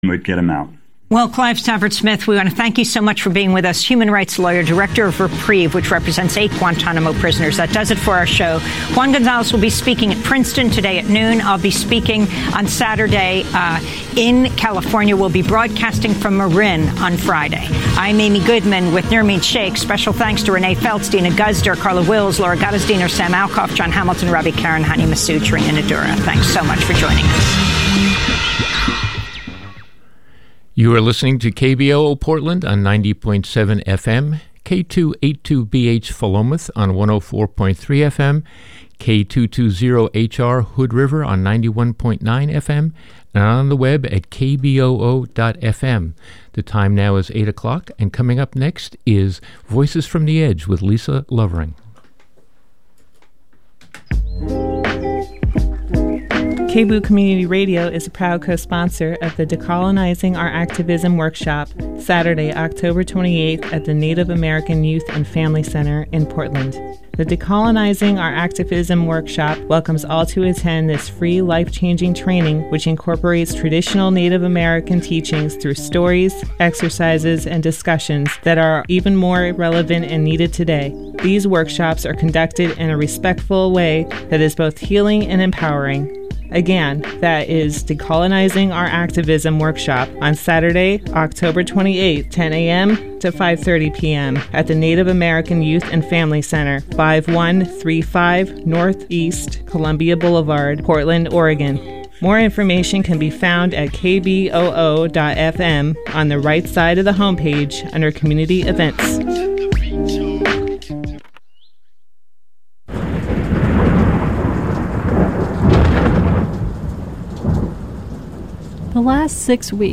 Progressive talk radio from a grassroots perspective